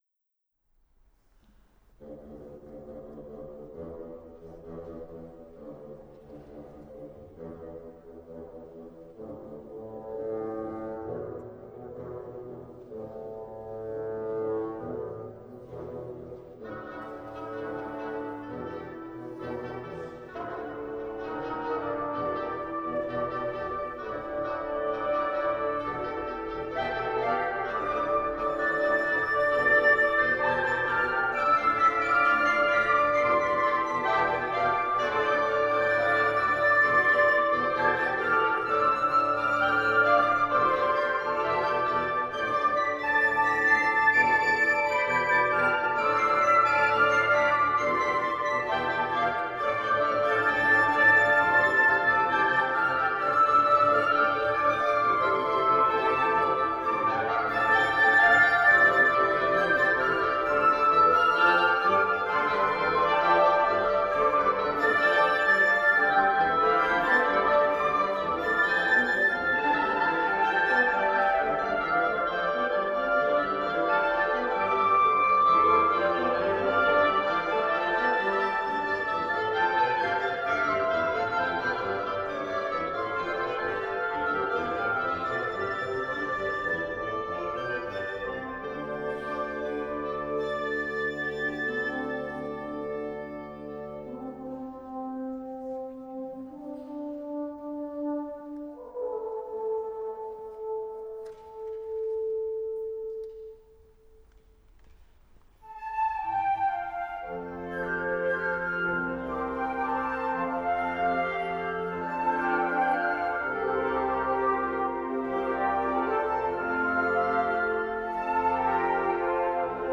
Croydononia - a musical tour of Croydon - for wind and brass